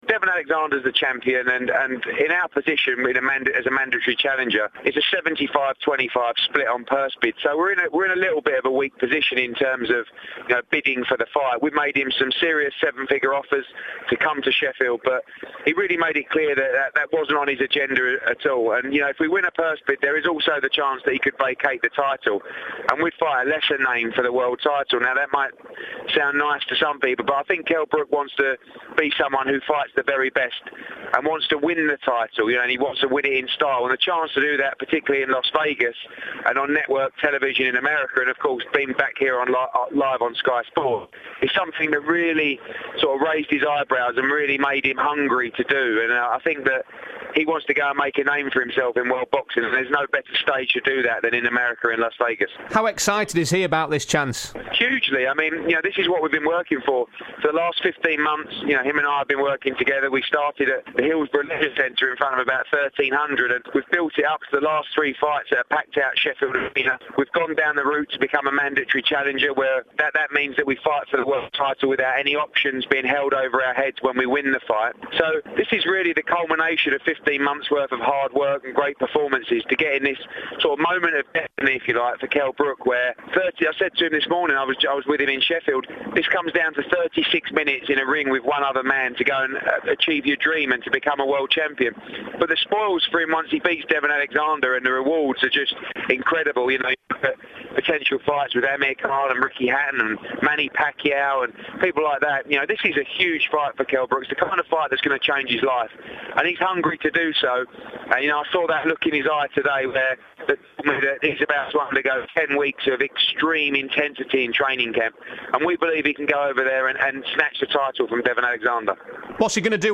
Eddie Hearn speaking to me about Kell Brook's next fight vs Devon Alexander